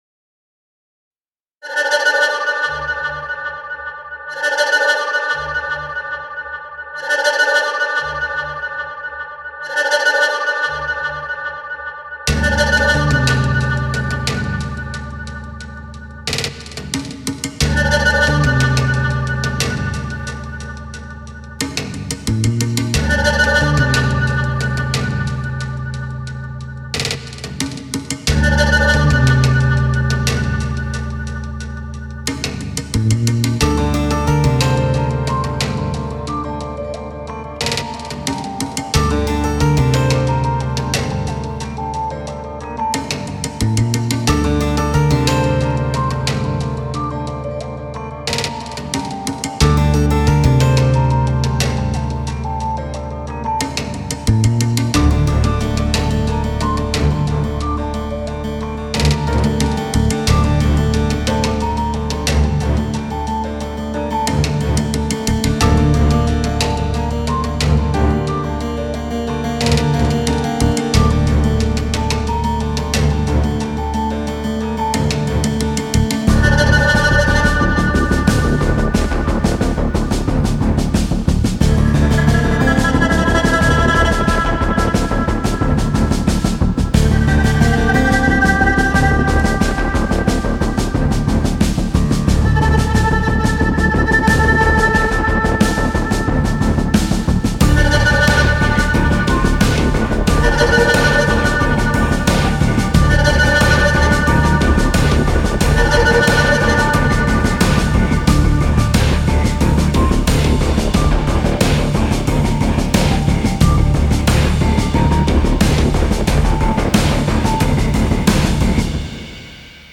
tema dizi müziği, heyecan gerilim aksiyon fon müzik.